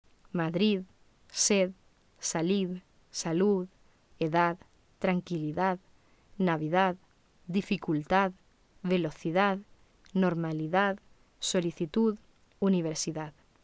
• Una variante aproximante [ð], en la que se produce una aproximación, pero no un cierre total, de los órganos articulatorios: la punta de la lengua toca suavemente los bordes de los dientes superiores, al tiempo que se produce la salida del aire de un modo suave y sin fricción.
[ð] en posición final